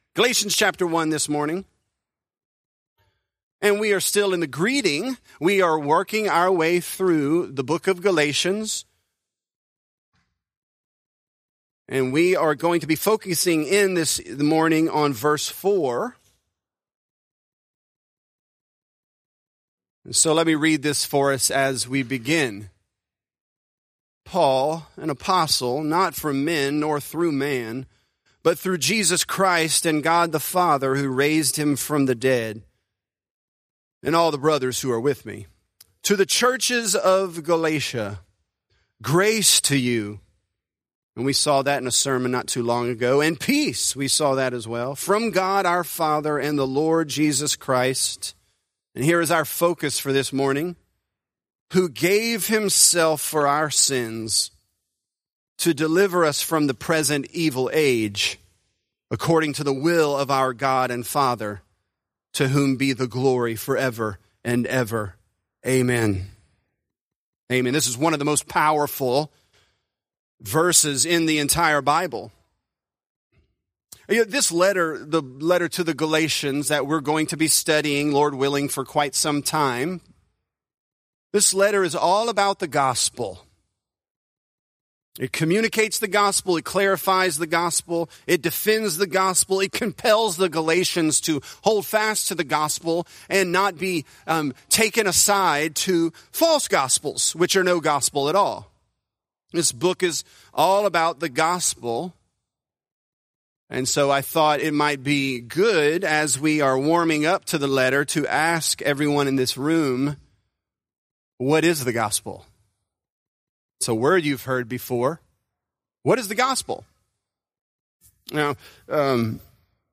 Galatians: Jesus Gave Himself | Lafayette - Sermon (Galatians 1)